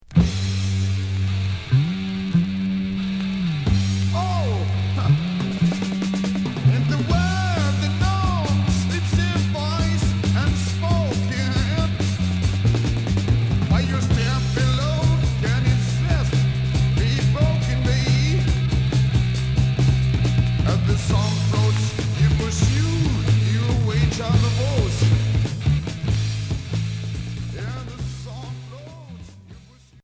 Heavy rock